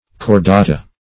Chordata \Chor*da"ta\, n. pl. [NL., fr. L. chorda cord.] (Zool.)